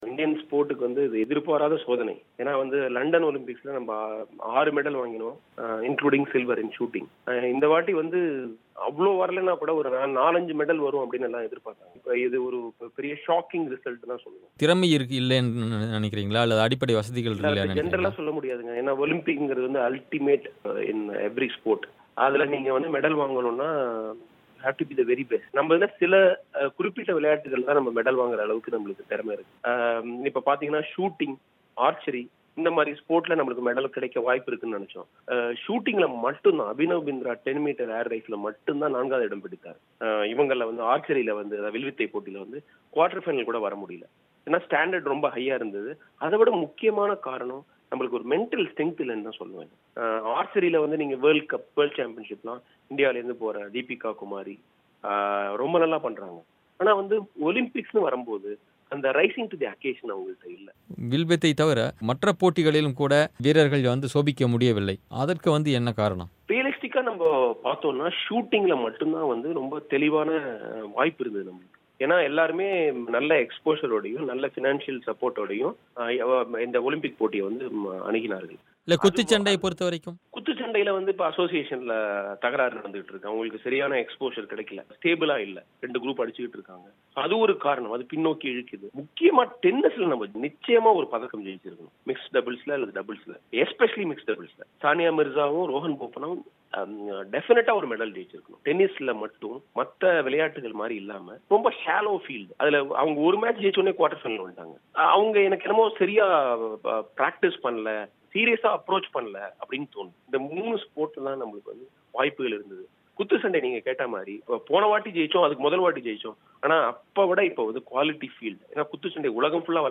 ரியோ ஒலிம்பிக் போட்டியில் இந்தியா ஒரு பதக்கம் கூடப்பெற முடியாமல் தடுமாறுவது ஏன்? பல்வேறு காரணங்களை ஆராய்கிறார் விளையாட்டுத்துறை பத்திரிகையாளர்